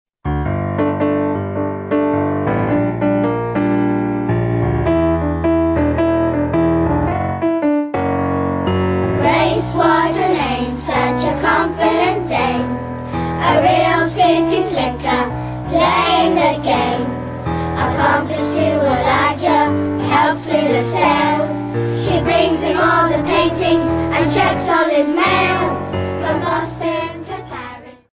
Sample from the Rehearsal CD
Full-length musical comedy for juniors/teenagers.